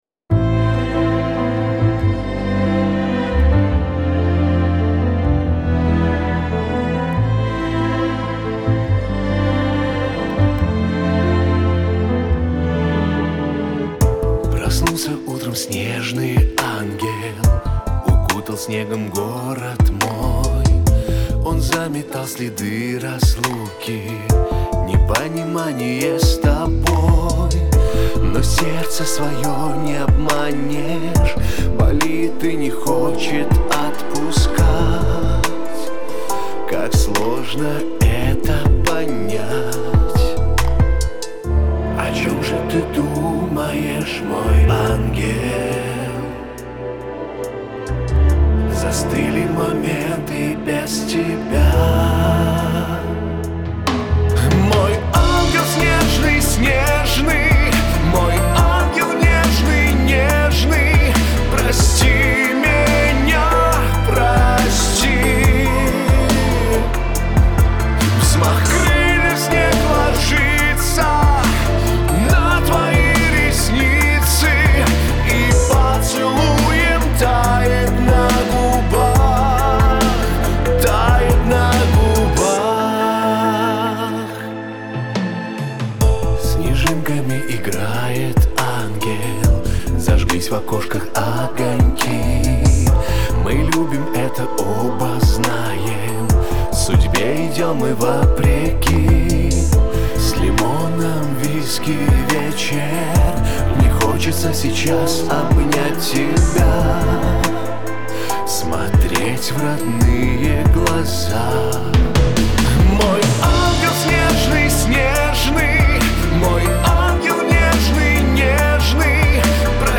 Жанр: Pop, Chanson